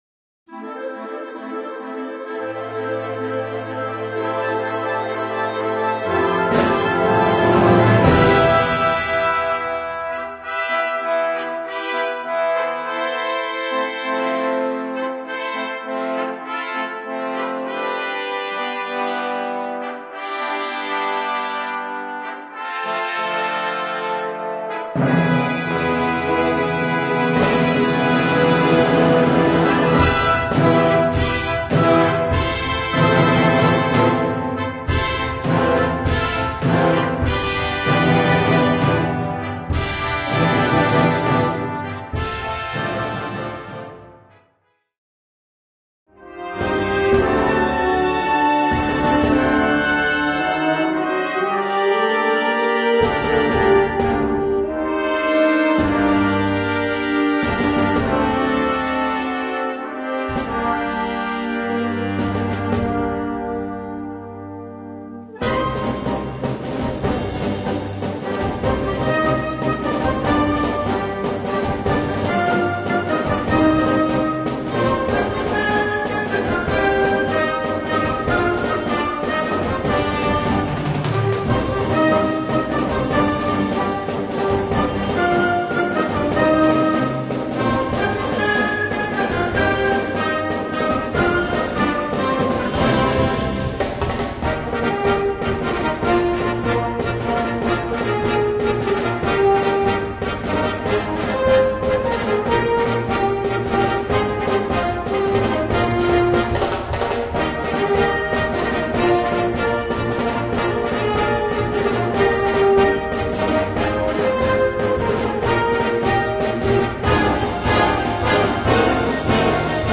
Kategorie Blasorchester/HaFaBra
Unterkategorie Konzertmusik
Besetzung Ha (Blasorchester)